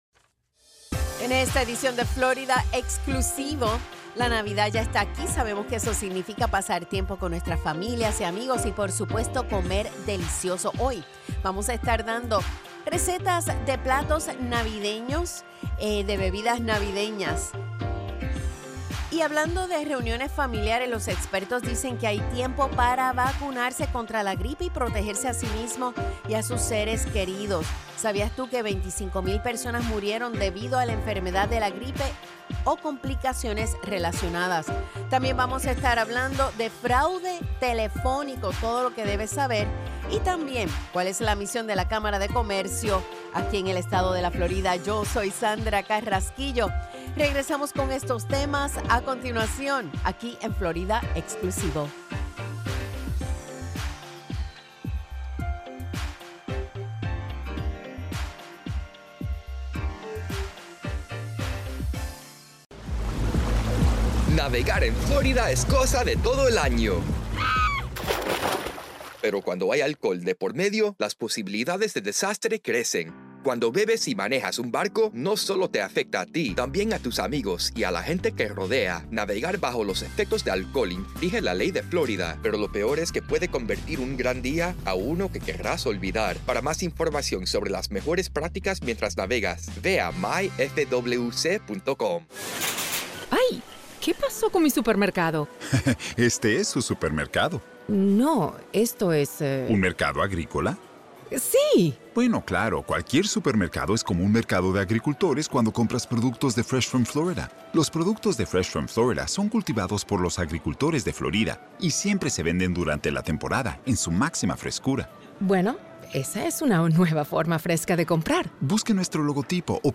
FNN's Florida Exclusivo is a weekly, one-hour news and public affairs program that focuses on news and issues of the Latino community.